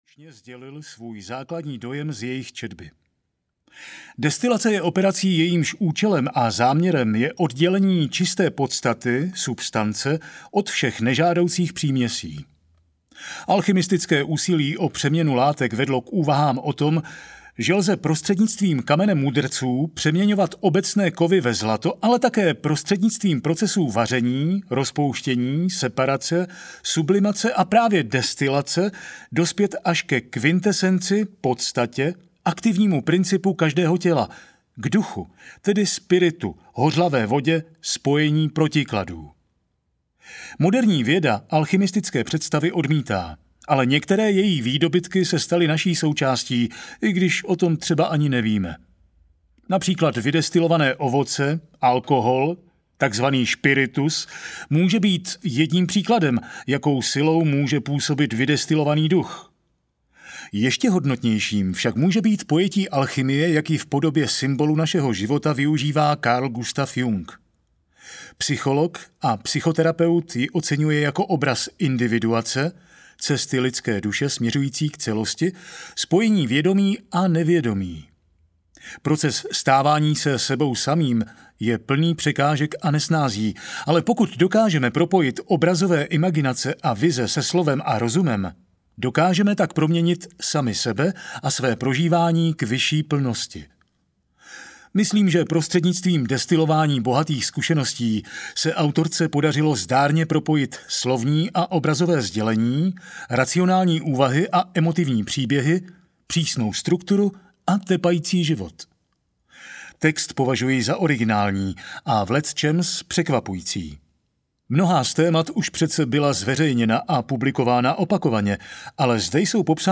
Učení prožitkem audiokniha
Ukázka z knihy